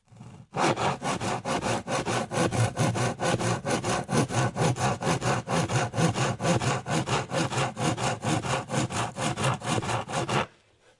描述：řezáníkovovétyčepomocípilyna kov
声道立体声